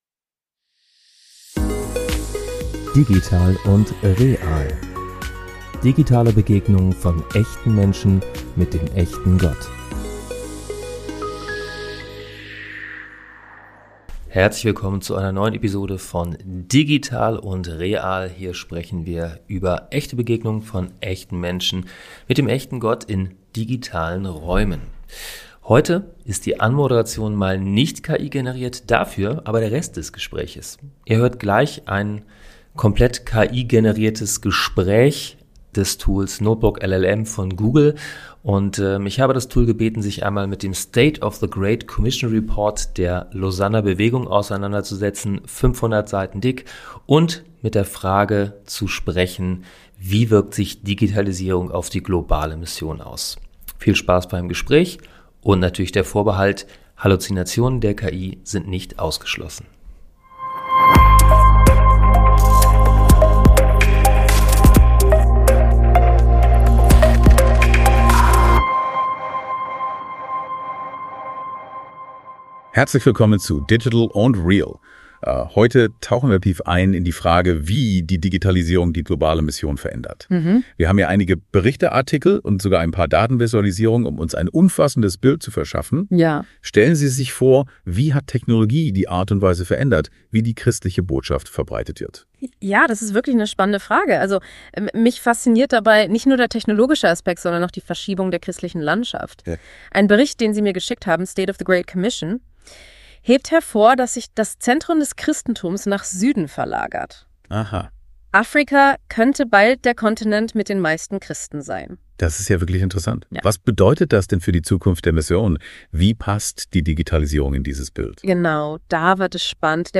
Digitalisierung und globale Mission | KI-generierter Talk zum State of the Great Commission Report ~ digital & real | by Allianz-Mission Podcast